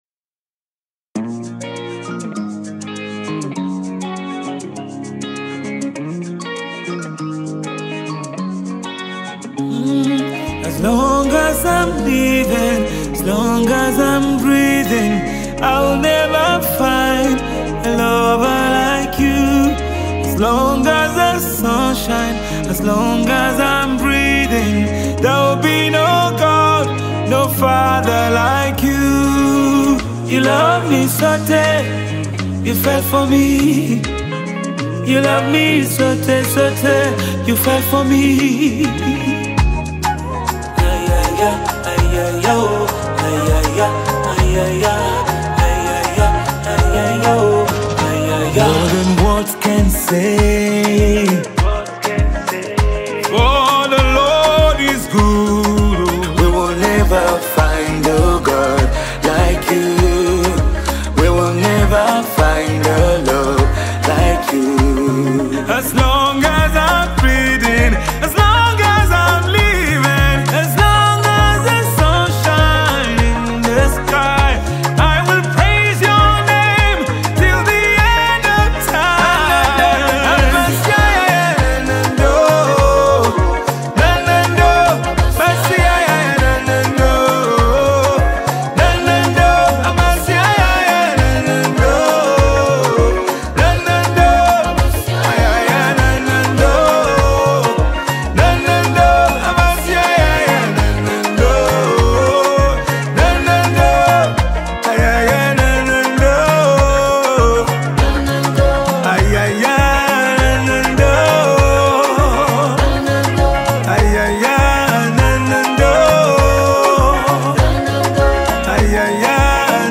Nigerian Gospel Songs
Genre:Gospel